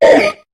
Cri de Makuhita dans Pokémon HOME.